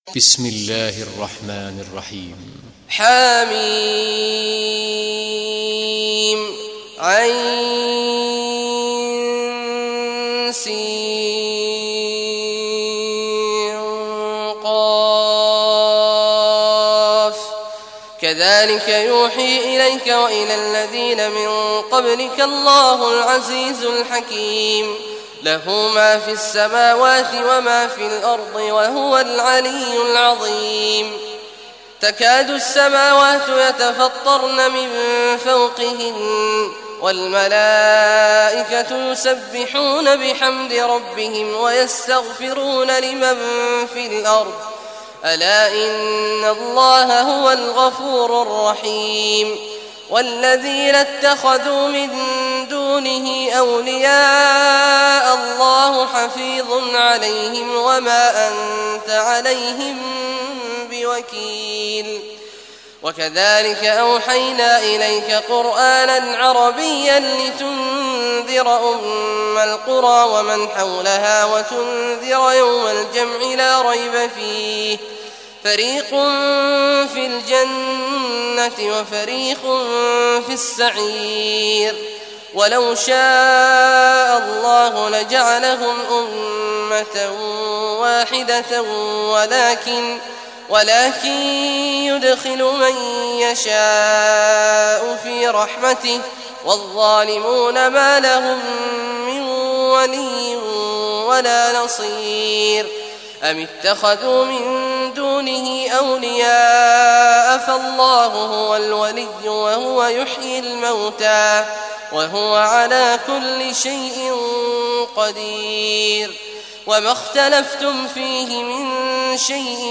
Surah Ash-Shuraa Recitation by Abdullah al Juhany
Surah Ash-Shuraa, listen or play online mp3 tilawat / recitation in Arabic in the beautiful voice of Sheikh Abdullah Awad al Juhany.